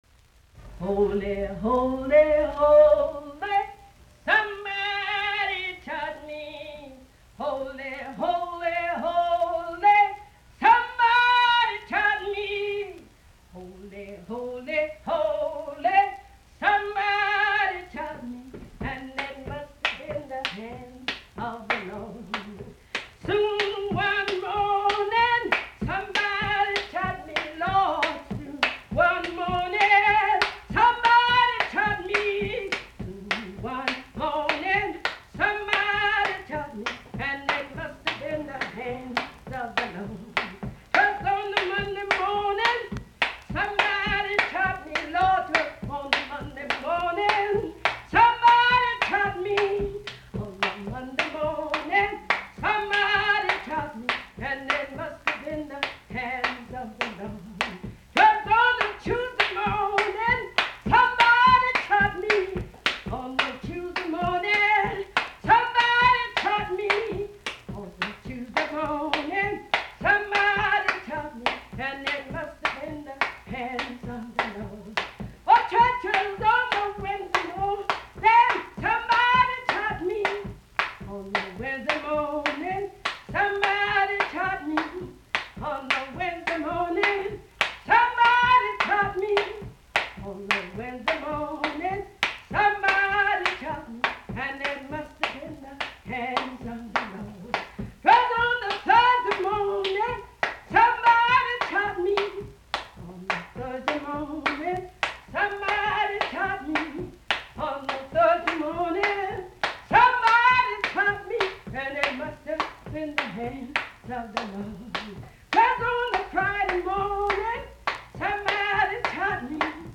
Music from the south : field recordings taken in Alabama, Lousiana and Mississippi.